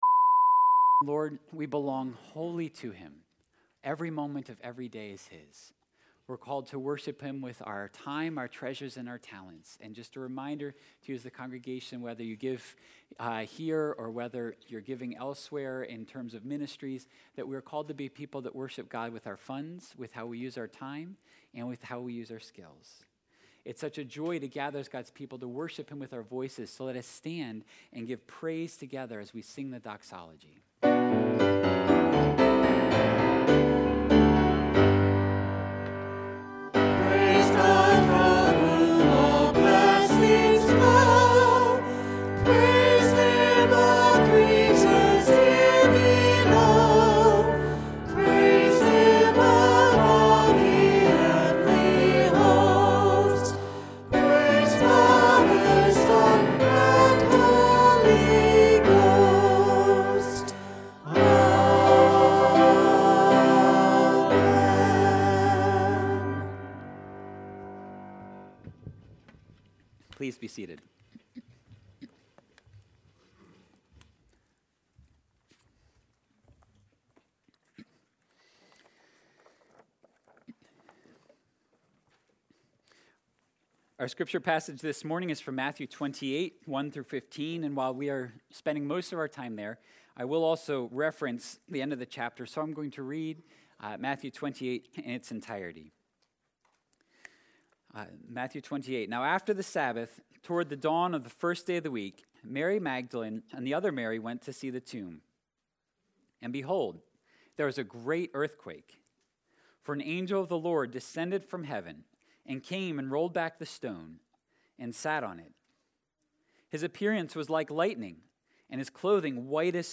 4.17.22-sermon-audio.mp3